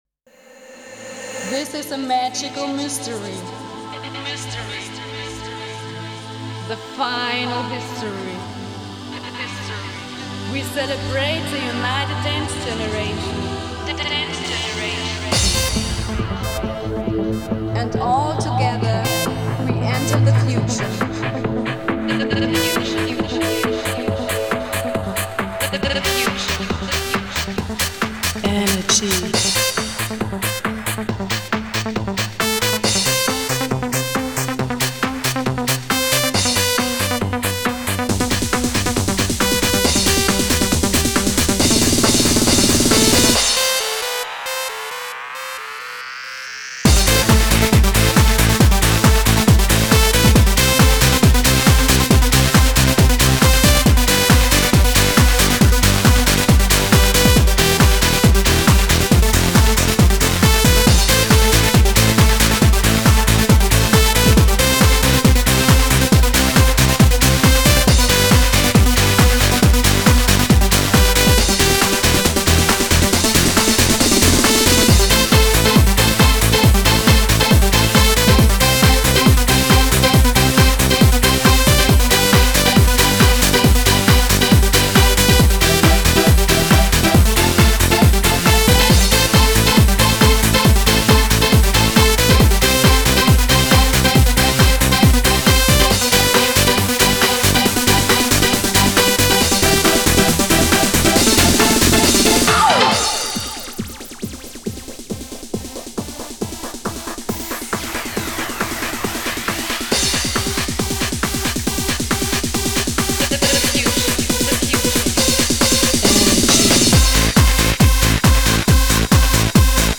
Genre: Trance.